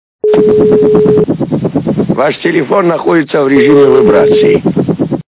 При прослушивании Cмешной мужской голос - Ваш телефон находится в режиме вибрации качество понижено и присутствуют гудки.
Звук Cмешной мужской голос - Ваш телефон находится в режиме вибрации